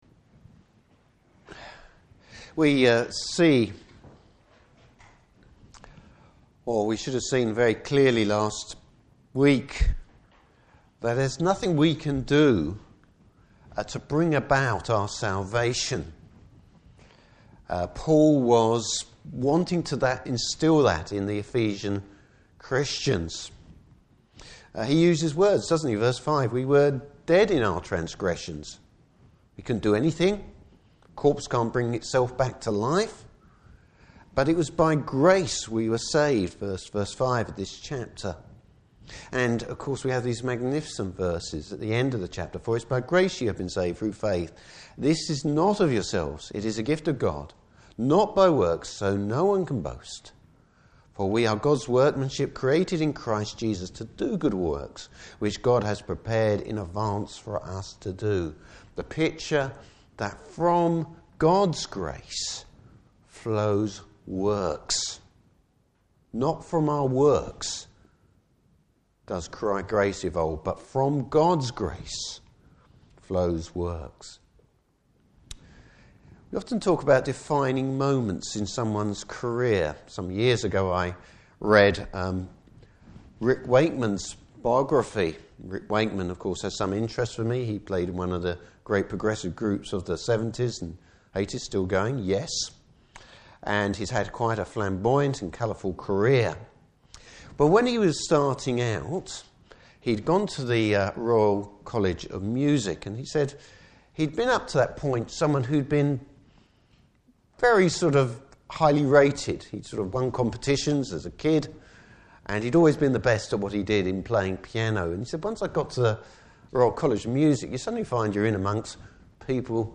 Service Type: Morning Service How was grace obtained?